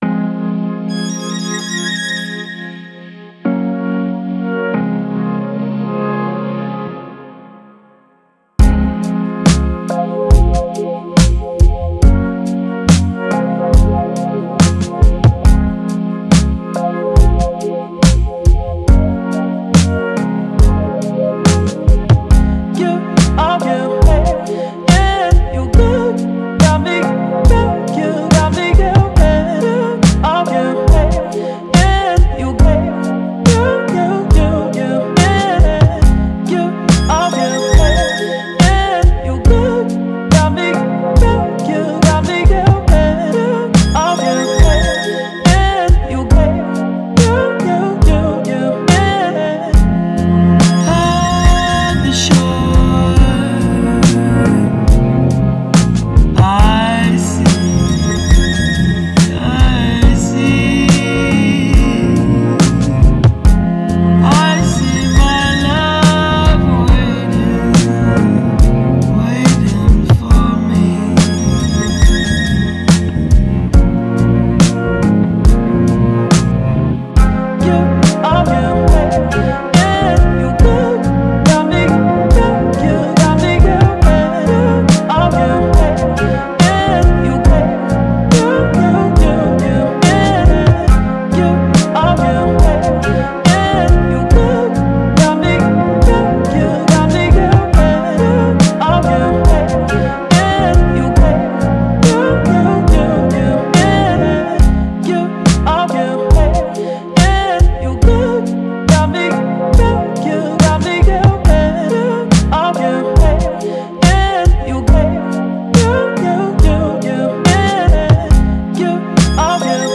Lofi, Downtempo, Vocal